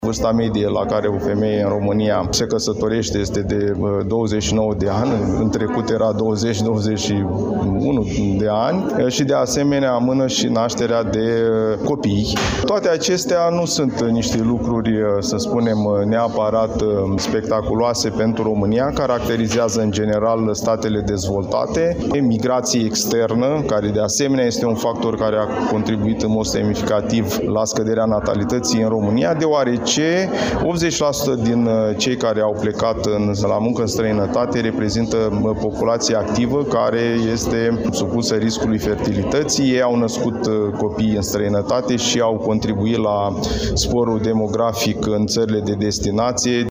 La Iași are loc, timp de trei zile, o conferință dedicată declinului demografic, la care participă specialiști din domeniul medical, economic și statistic.